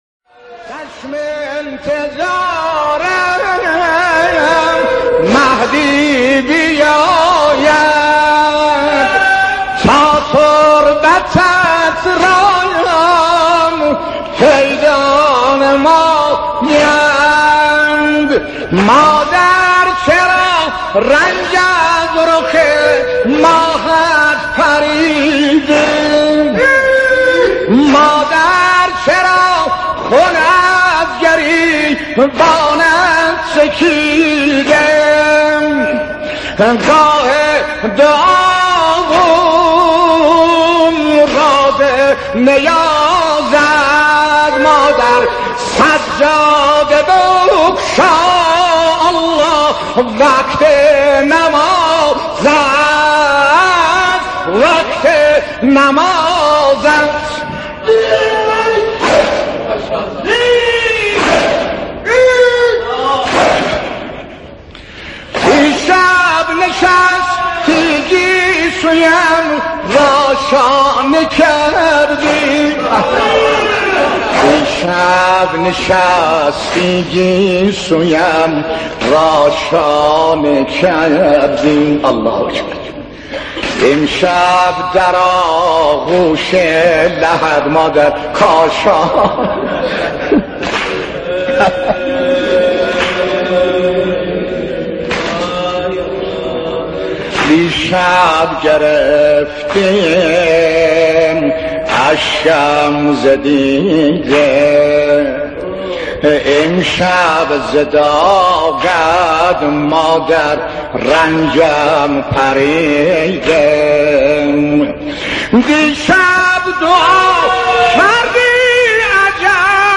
مداحی بسیار زیبا
نوحه قدیمی